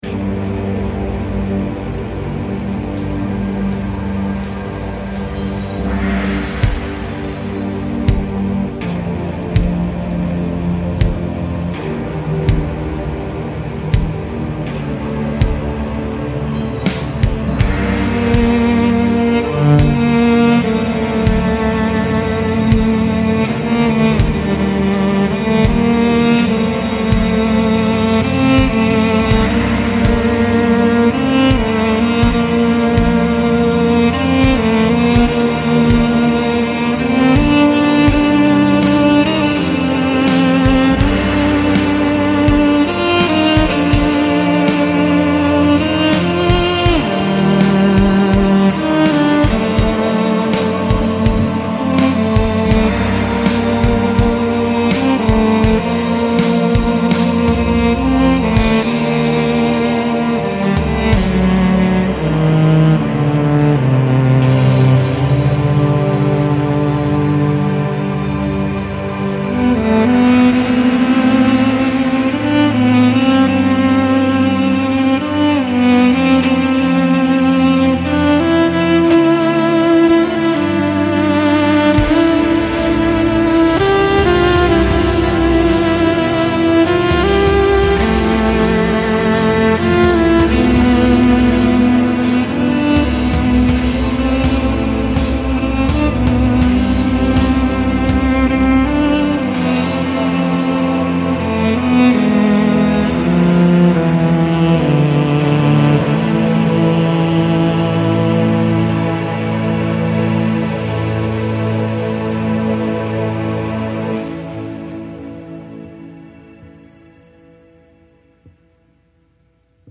immer-wieder-cello--01.mp3